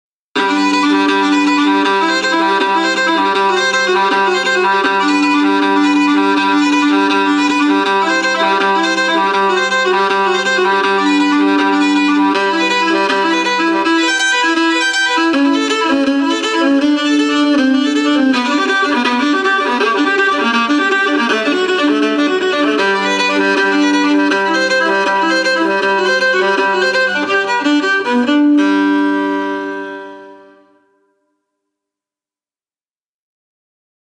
Allegro [140-150] tristesse - violon - vivant - pub - ancien
vivant - pub - ancien